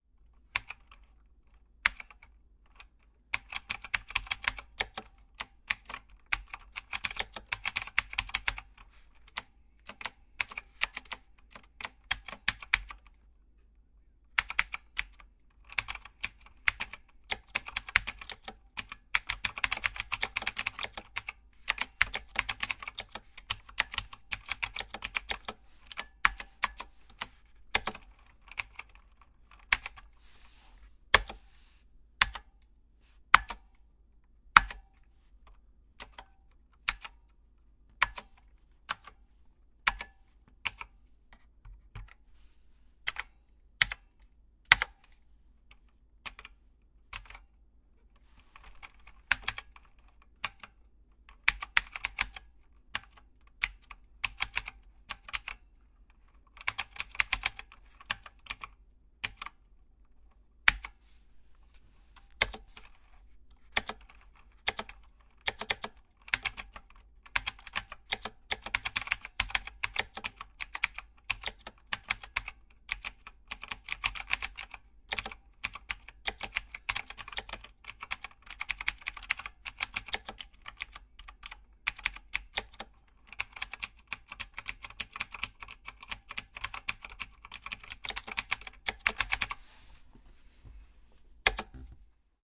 电脑键盘 " 电脑键盘 打字的声音
描述：这些是计算机键盘输入声音。
使用Rode NT1和使用Razor Blackwidow Ultimate键盘进行重新编码。
Tag: 按键 键盘 打字机 计算机 QWERTY 打字